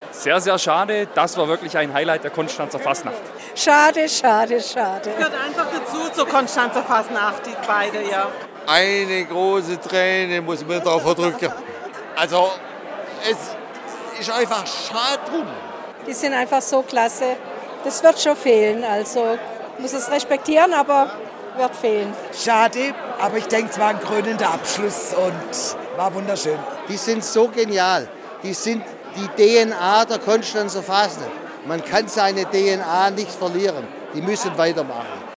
Dem Publikum wurden fast vier Stunden Live-Musik, Tänze, Sketche und Büttenreden geboten.